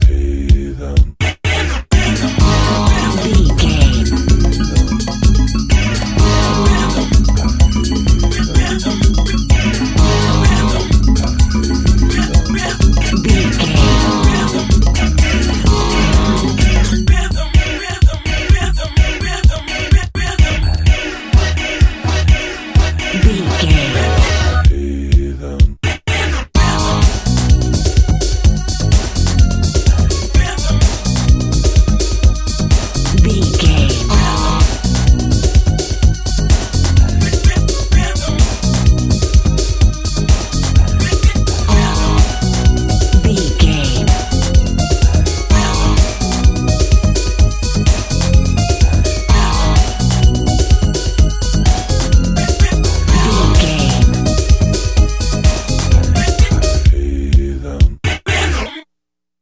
Aeolian/Minor
E♭
synthesiser
90s